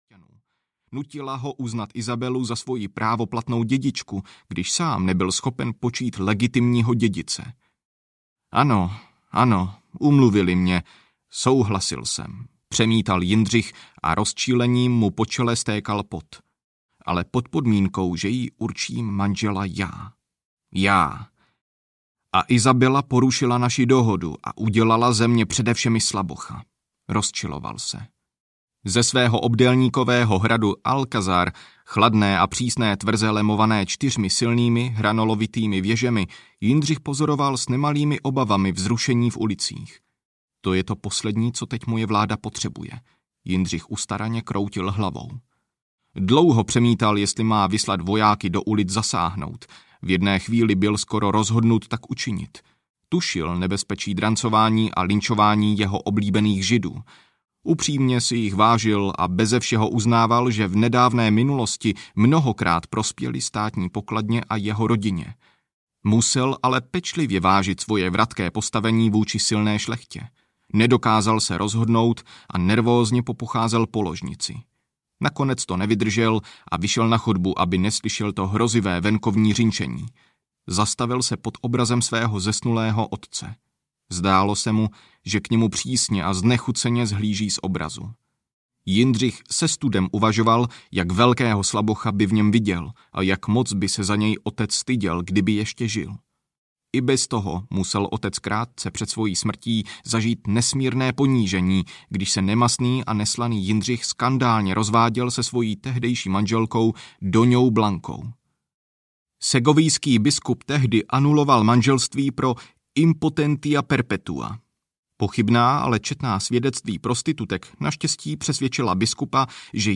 1492: Dobytí Granady audiokniha
Ukázka z knihy